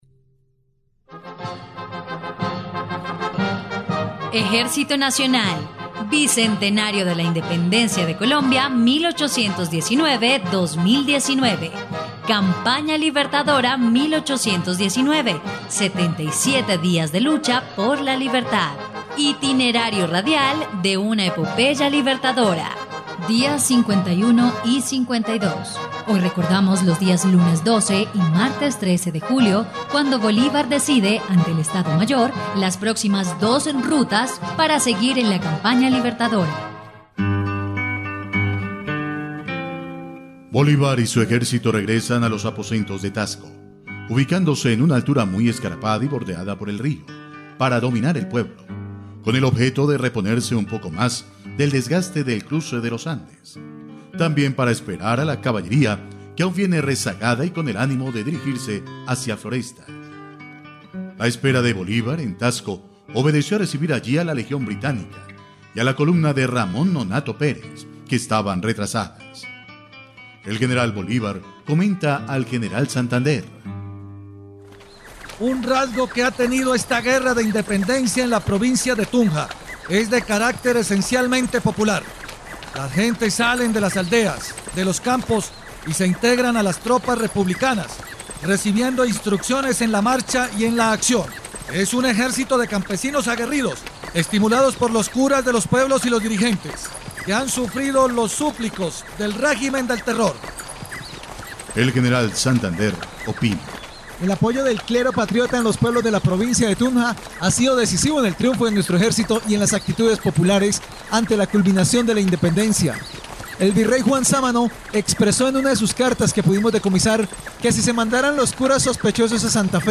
dia_51_y_52_radionovela_campana_libertadora.mp3